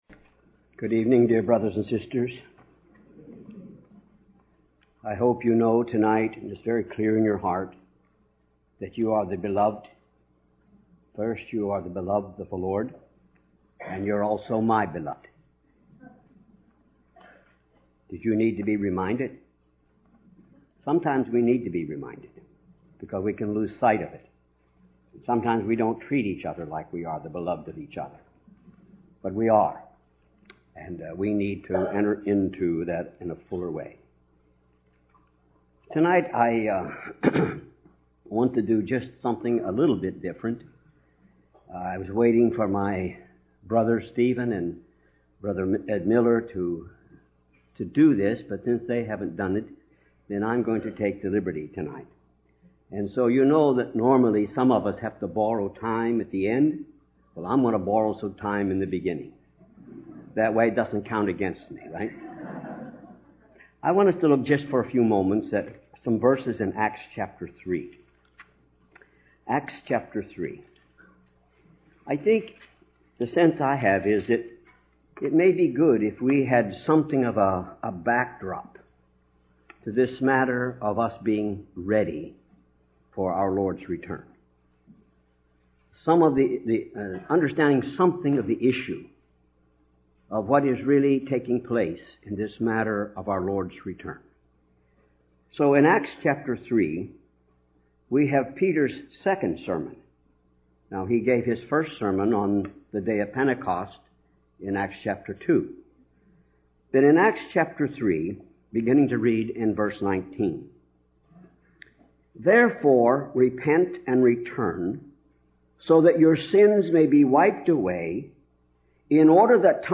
Harvey Cedars Conference
Message